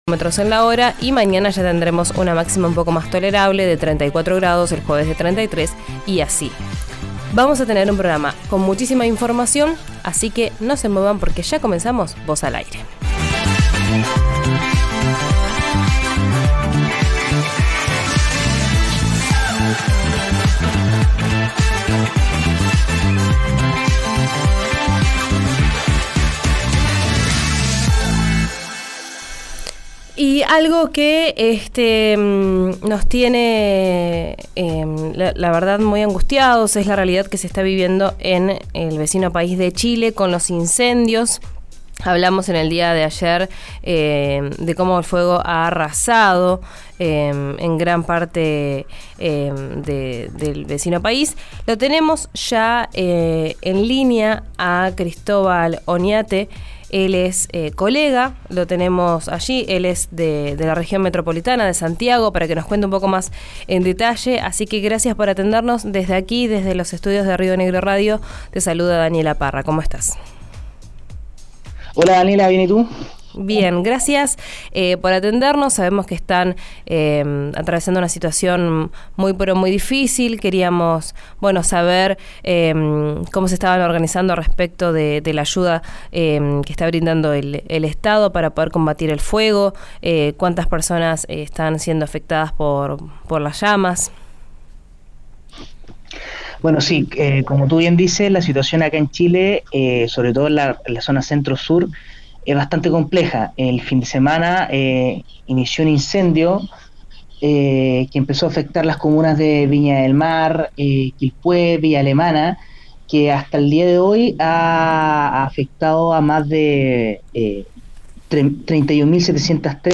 En comunicación directa desde Chile, sostuvo que la ayuda por parte del gobierno «se ha tardado un un poco, un poco bastante la verdad, sobre todo por la logística», ya que es muy difícil acceder «a la cima del de los cerros de Viña del Mar».